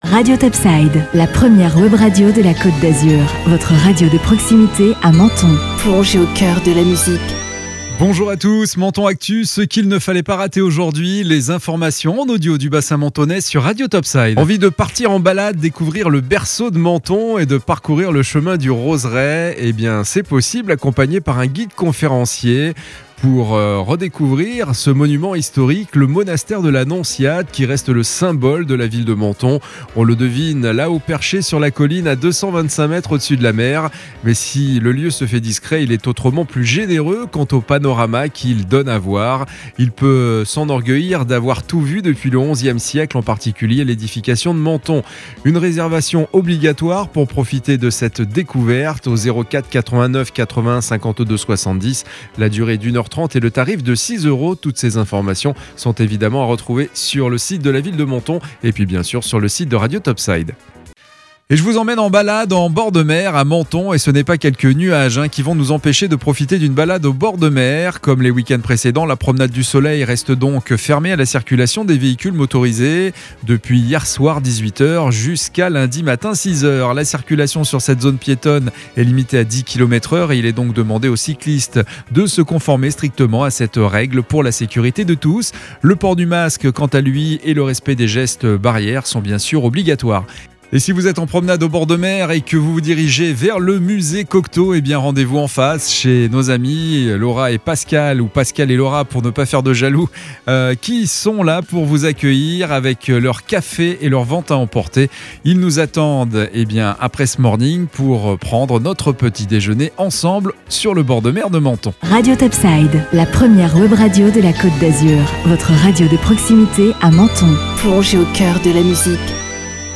Menton Actu - Le flash info du samedi 01 mai 2021